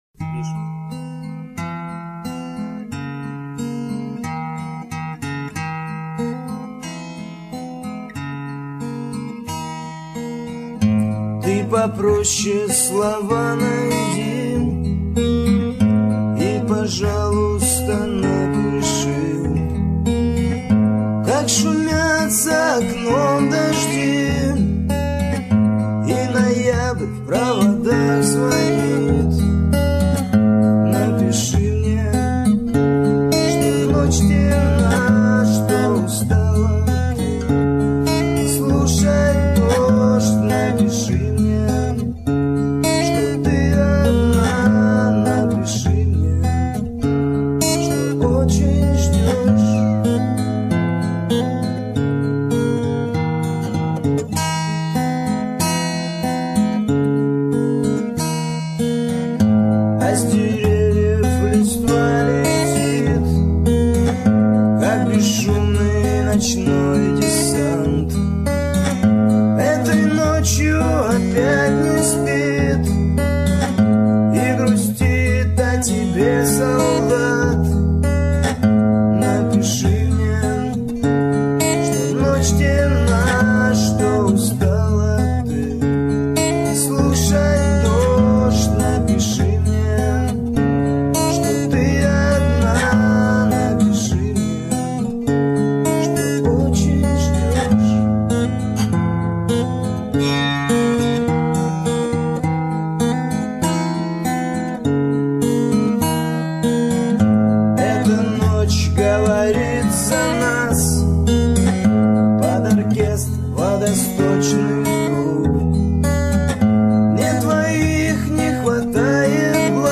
dvorovie_pesni_pod_gita___napishi_mne_z2_fm.mp3